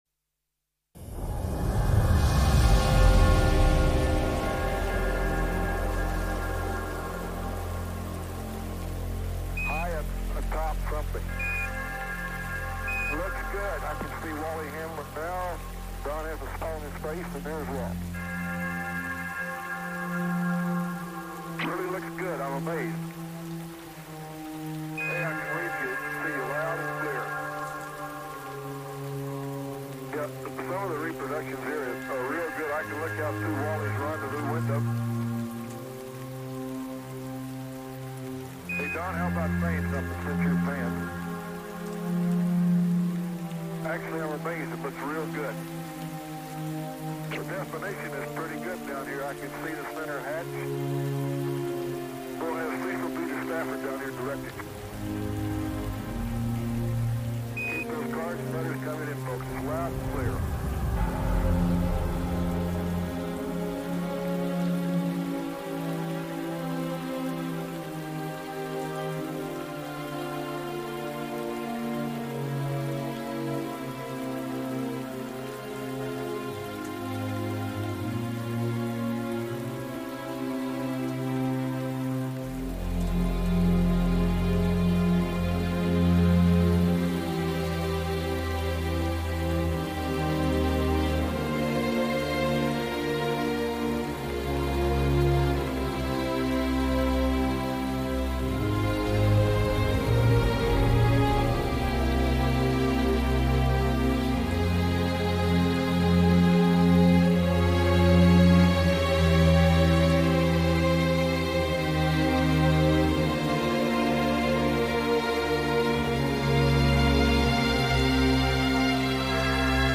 Little trance show recorded live
Vocals Trance Hard Trance